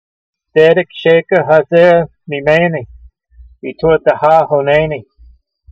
ho = o sound in honnaynee (notice the dagesh in the “nun”, which doubles it): vowel kamayts katoof Ges. § 8c
v29_voice.mp3